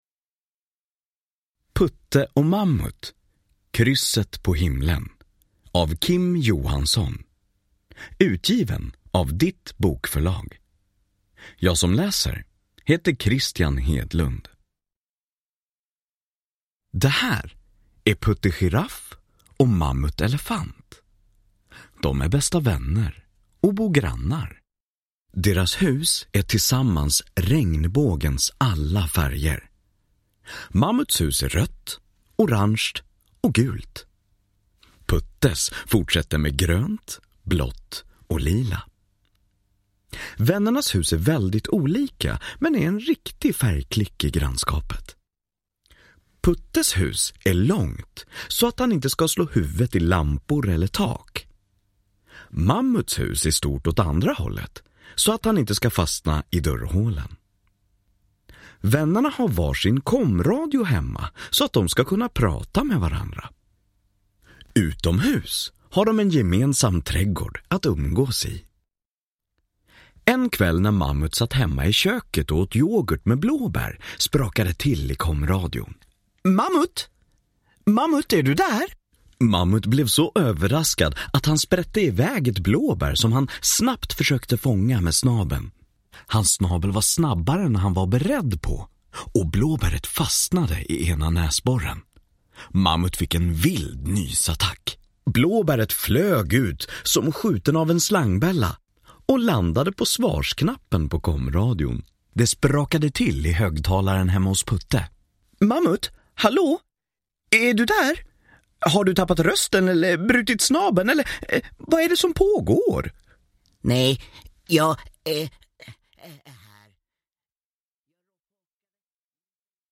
Putte och Mammut, krysset på himlen – Ljudbok